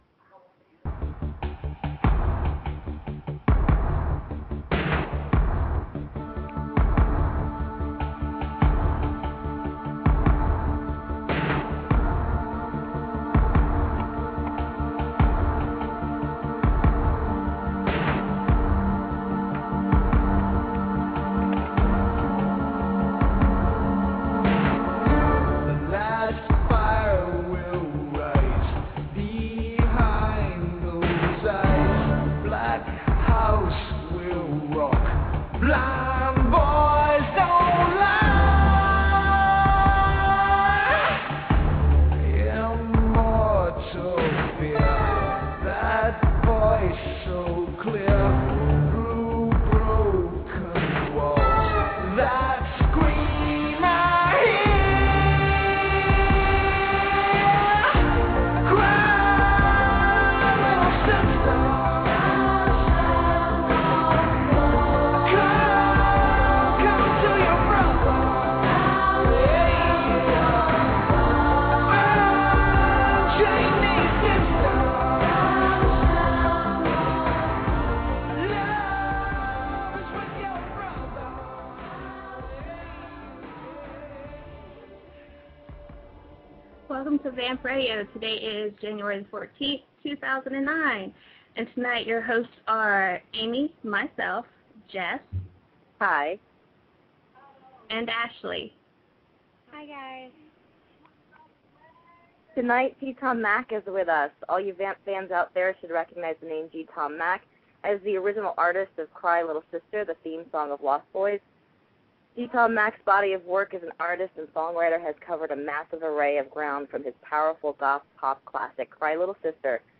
Corey Haim & G Tom Mac Interview